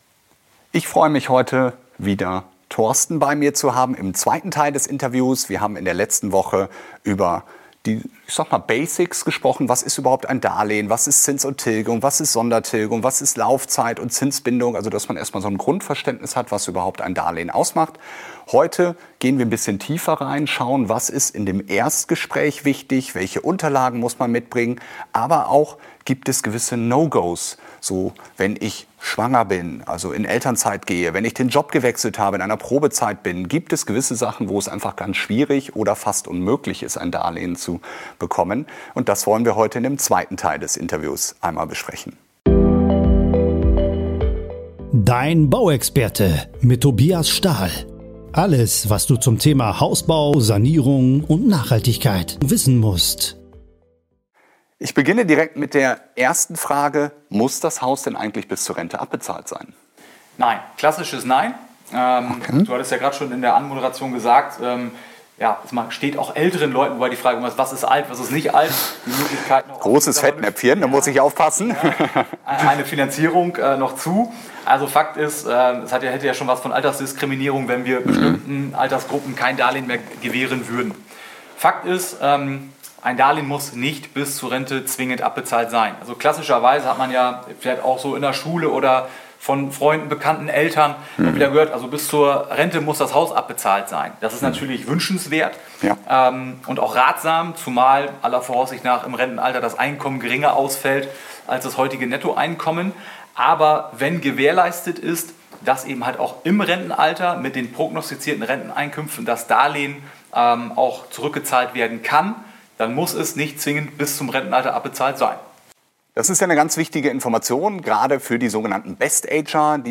Nr. 69 - Was sind die größten Ängste bei der Finanzierung? - Interview